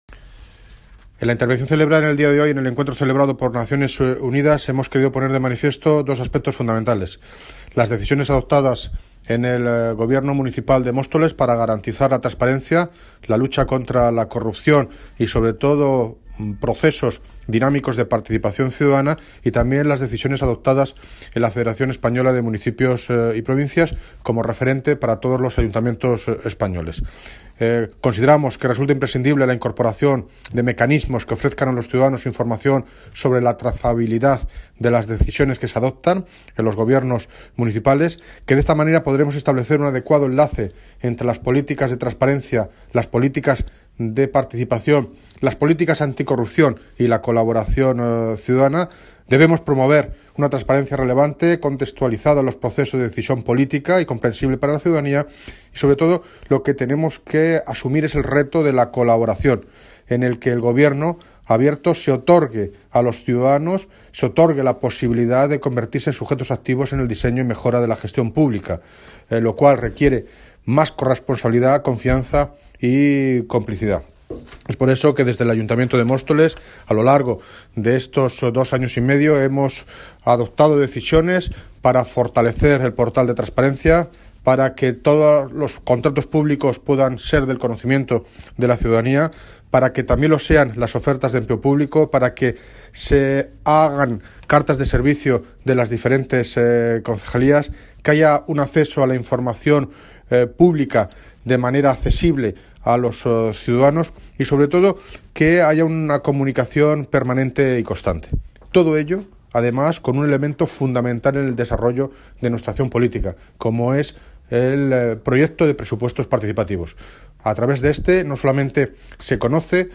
Audio - David Lucas (Alcalde de Móstoles) Sobre Conferencia de los Estados